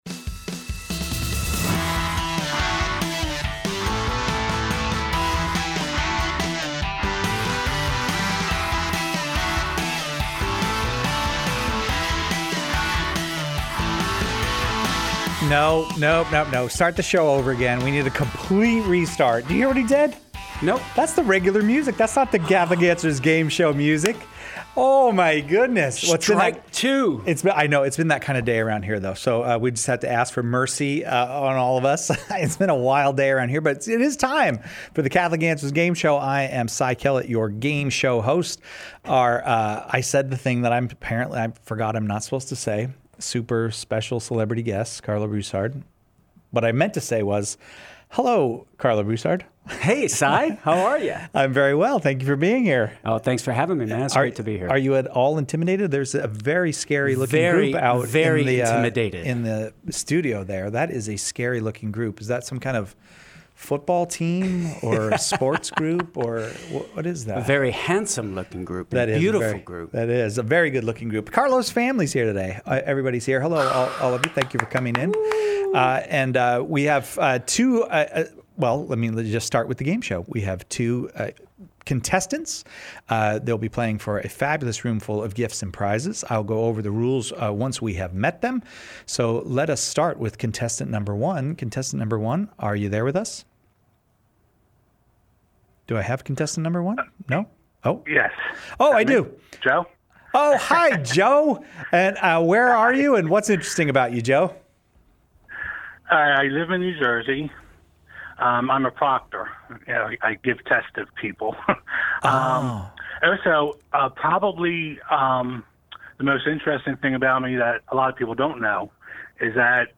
Catholic Answers Game Show LIVE